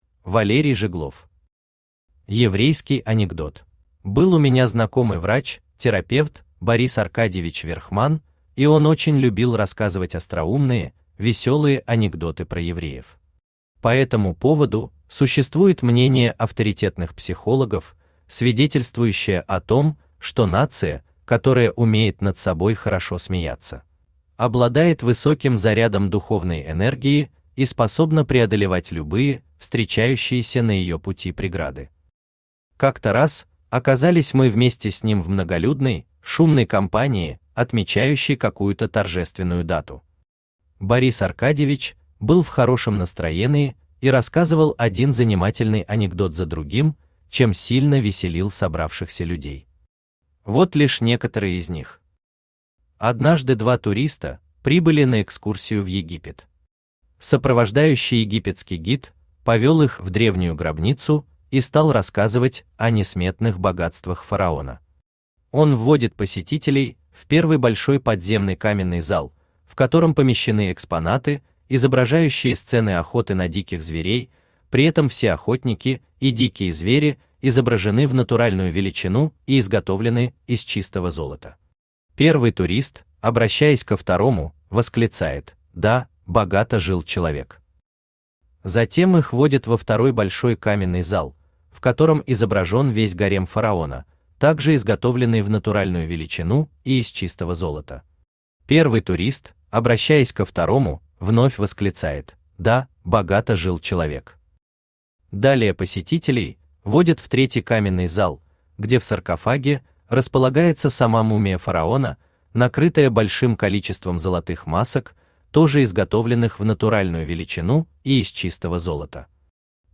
Аудиокнига Еврейский анекдот | Библиотека аудиокниг
Aудиокнига Еврейский анекдот Автор Валерий Жиглов Читает аудиокнигу Авточтец ЛитРес.